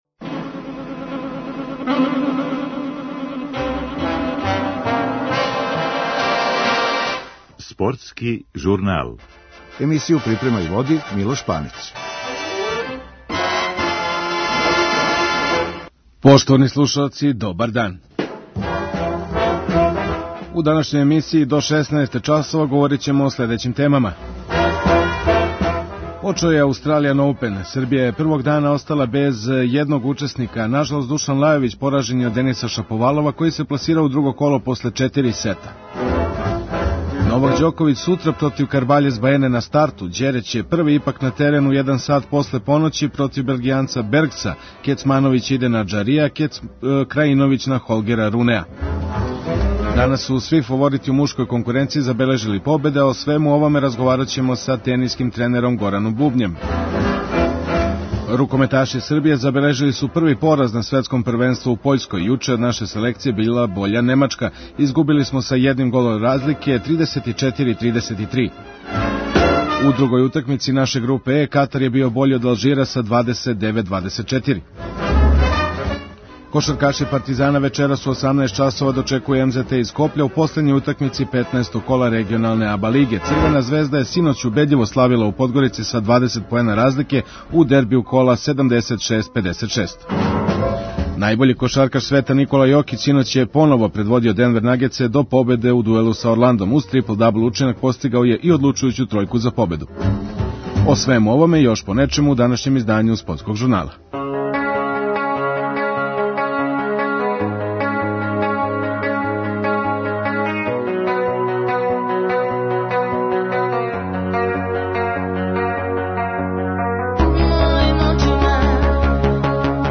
Данас су сви фаворити у мушкој конкуренцији забележили победе. О свему овоме разговараћемо са тениским тренером